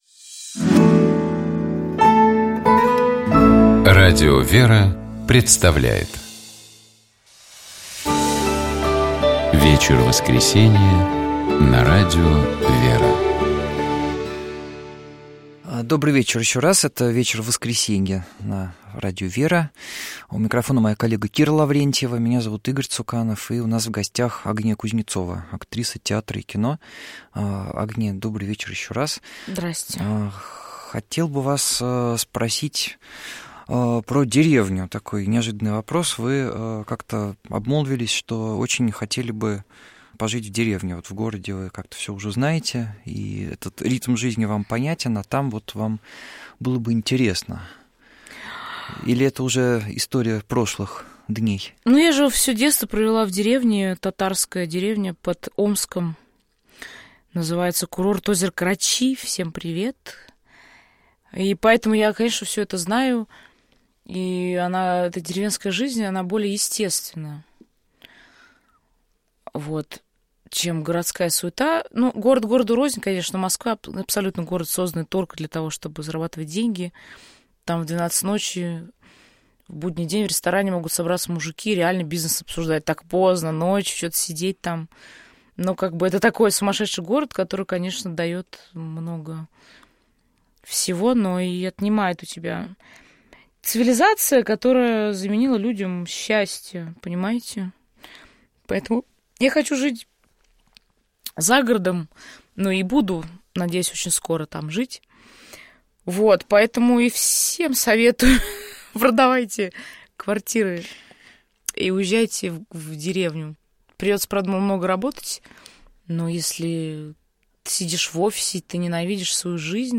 У нас в гостях была актриса театра и кино Агния Кузнецова.